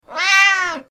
دانلود صدای گربه ناراحت و غمگین از ساعد نیوز با لینک مستقیم و کیفیت بالا
جلوه های صوتی